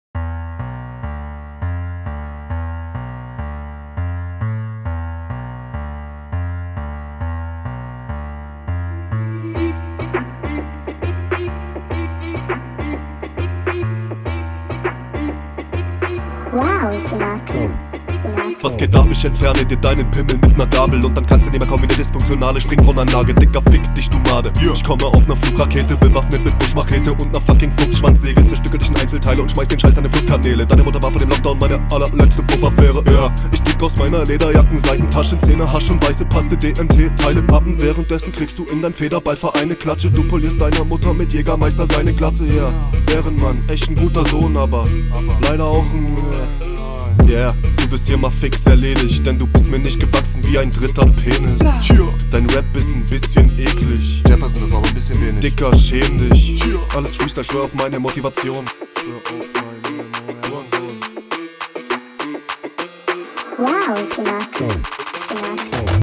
sound gefällt mir hier schonmal besser. du passt halt einfach besser auf den beat den …
Ein paar Flowfehler für mich bzw Stellen die mich bisschen raushauen.